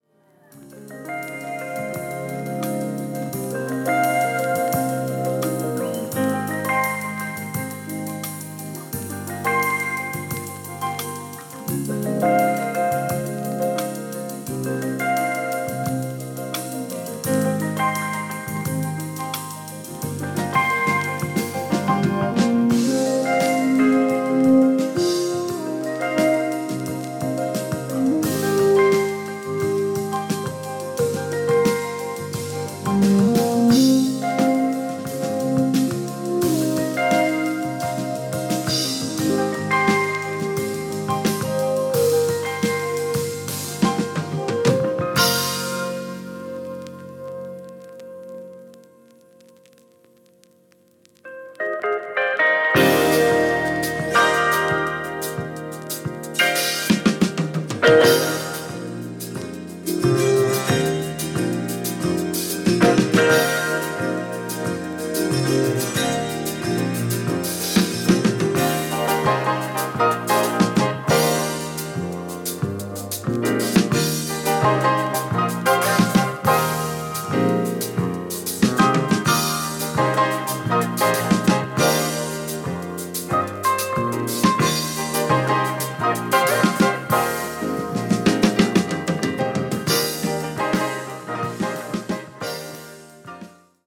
Bass
Drums
Piano, Synthesizer
Guitar